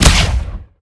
fire_gun4_rank3.wav